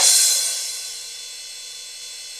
CYM XCHEEZ09.wav